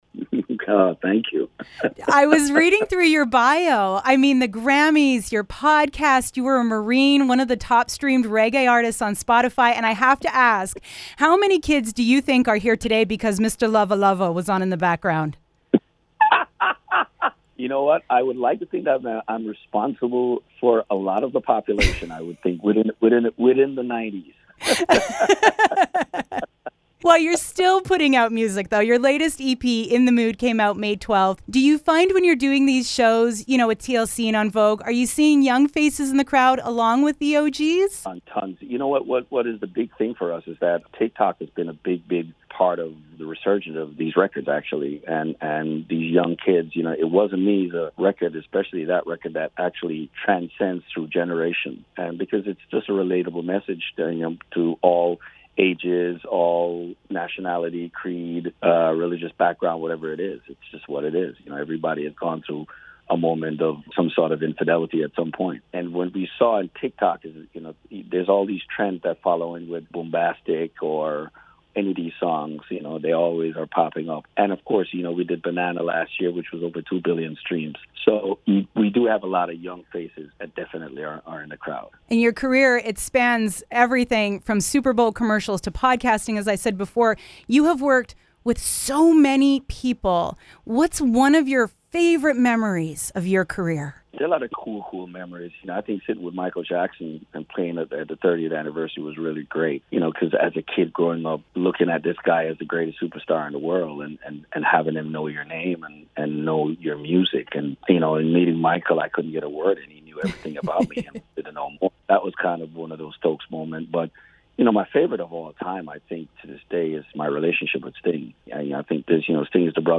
Shaggy is teaming up with TLC for the Hot Summer Nights Tour, rolling into Edmonton July 13th at the Expo Center. He took some time out of a busy day doing a podcast, touring and travelling to chat with me about his career, this show and what he’s got on the horizon: